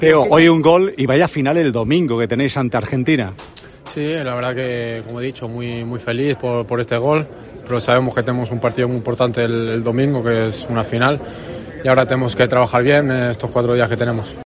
Goleador en la semifinal